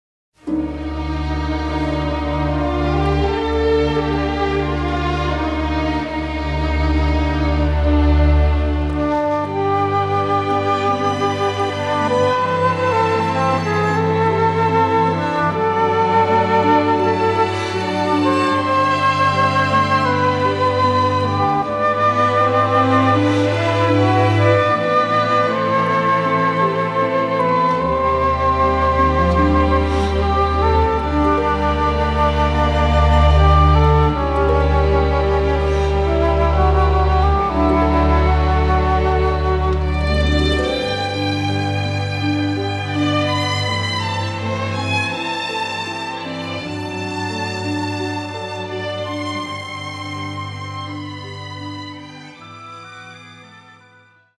score is a highly dramantic one